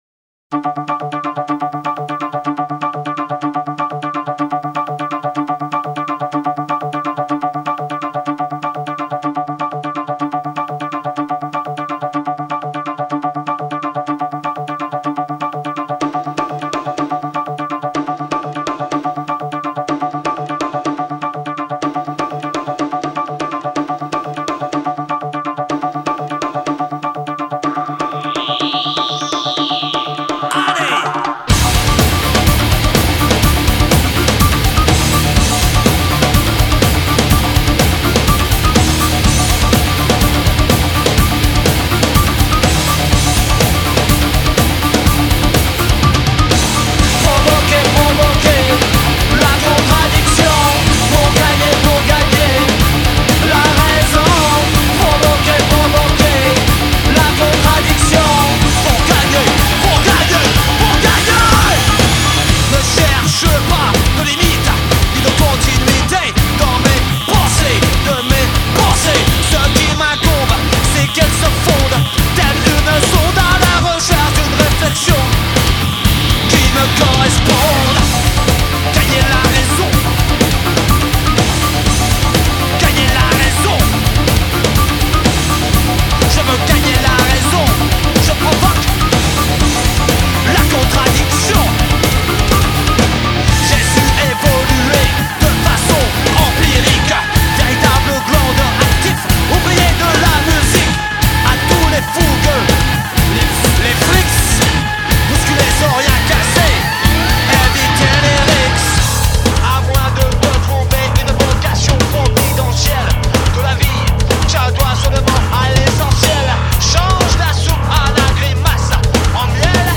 La fusion est omniprésente à cette époque
des influences techno, indus, hardcore et world